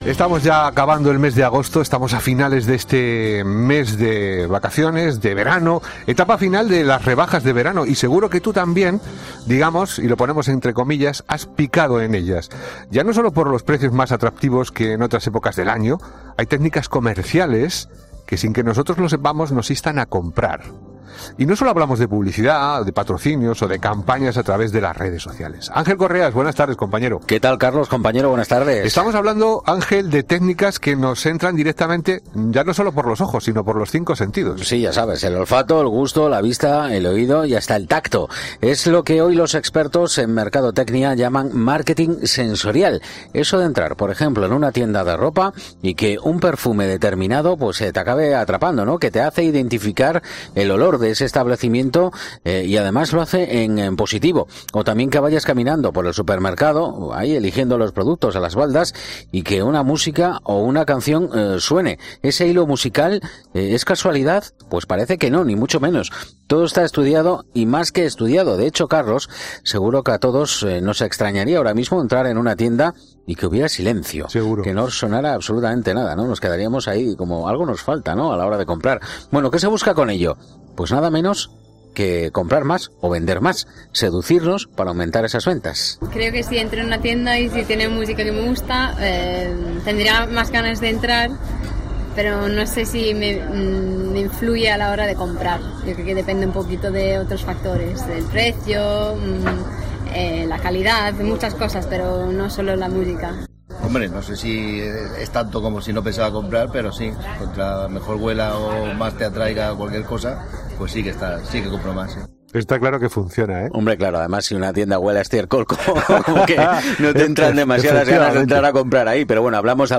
Una neuropsicóloga y una especialista en marketing explican las campañas en "Herrera en COPE"